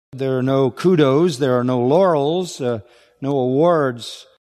This means that the final s is pronounced as a z, as in photos and commandos: